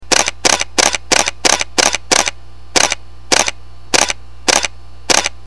Here are two audio files (.mp3 format) which give you some impression of the shutter sound of the EOS 30D and what happens when the buffer fills up.
End of buffer fills and frame rate drops. 3fps JPEGs.